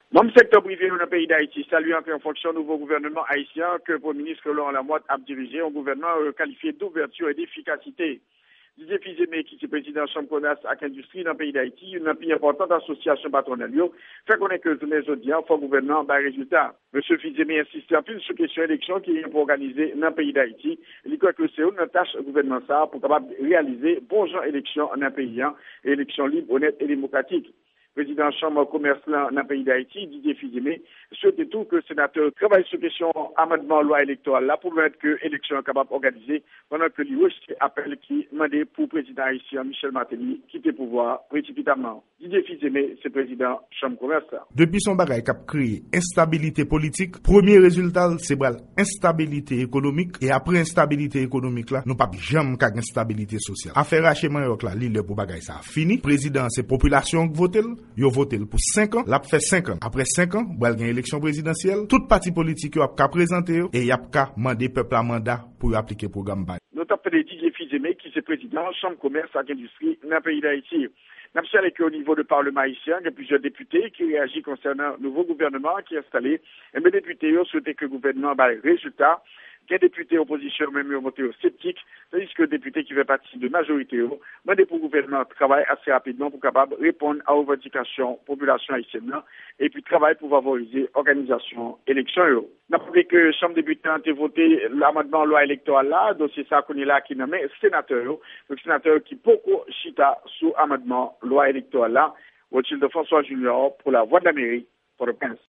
Repòtaj